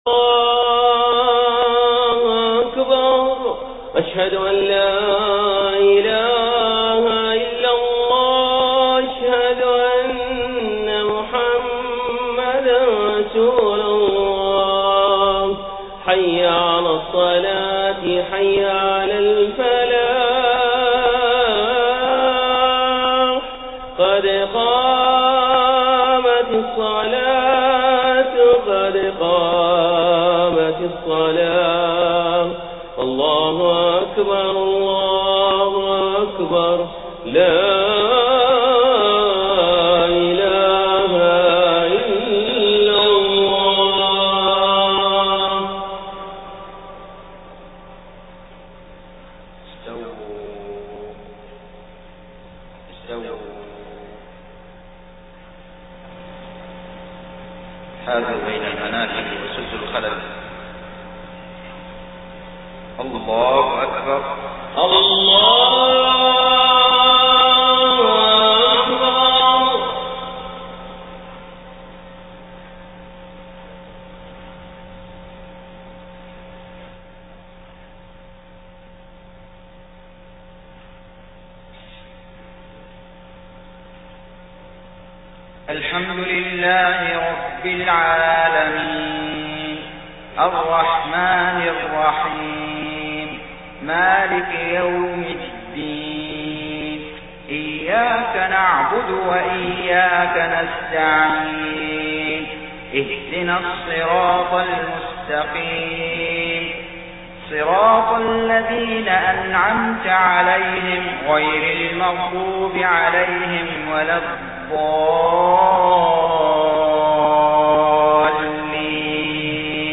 صلاة العشاء 20 محرم 1430هـ فواتح سورة القصص 1-14 > 1430 🕋 > الفروض - تلاوات الحرمين